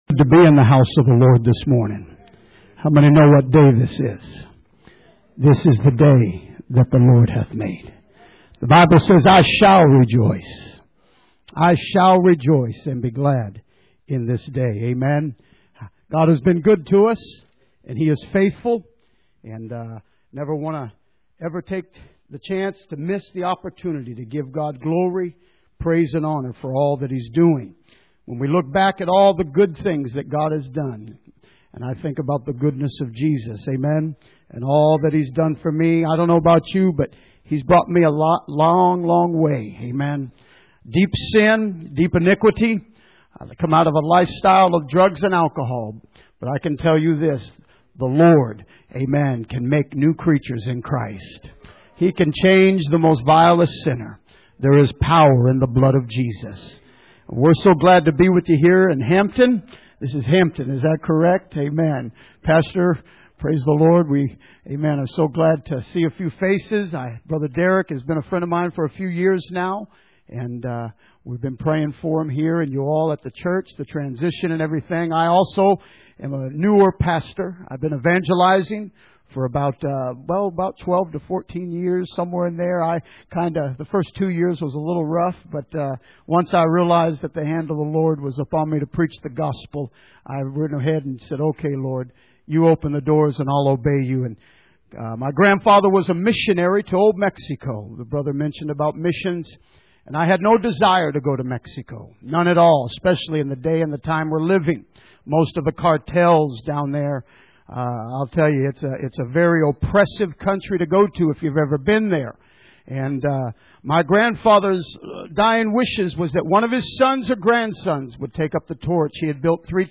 Passage: John 11:21-42 Service Type: Sunday Morning